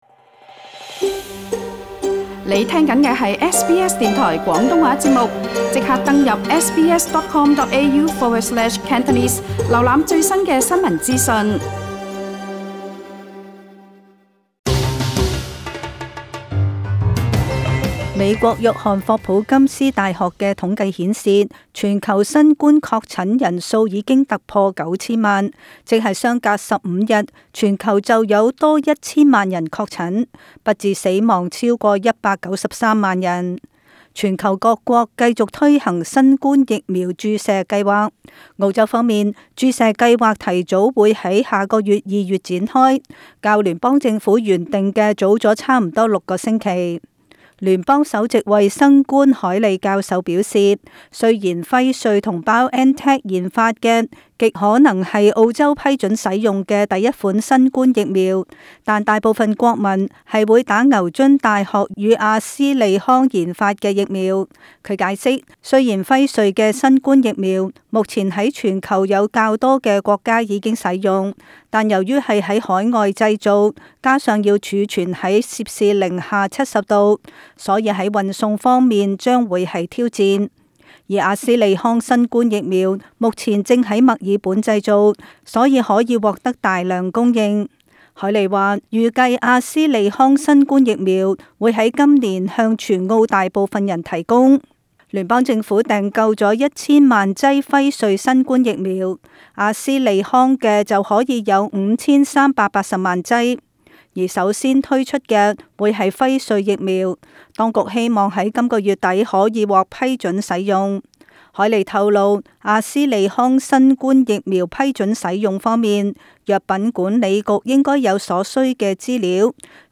【时事报导】